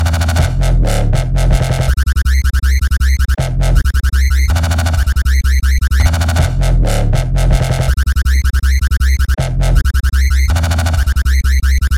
大规模摇摆测试
标签： 160 bpm Dubstep Loops Bass Wobble Loops 2.02 MB wav Key : Unknown
声道立体声